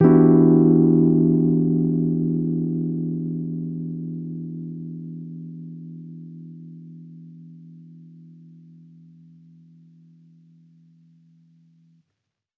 Index of /musicradar/jazz-keys-samples/Chord Hits/Electric Piano 1
JK_ElPiano1_Chord-Em9.wav